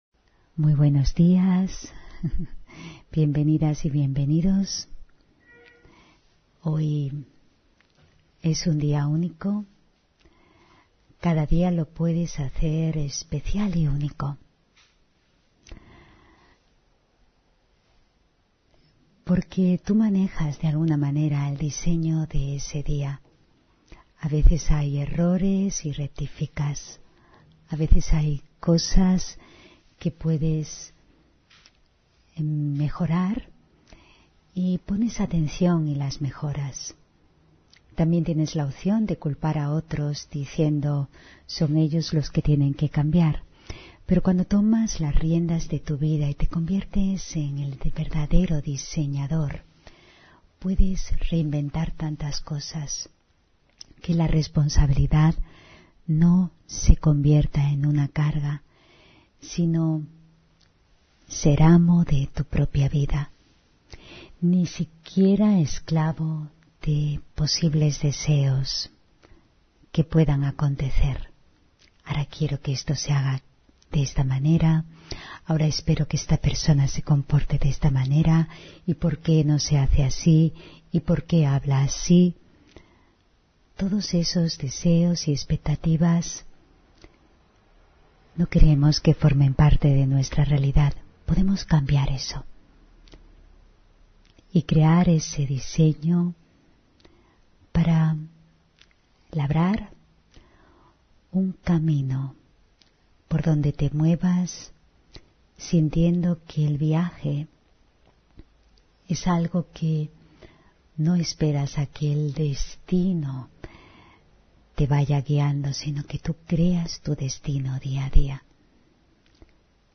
Meditación de la mañana: El camino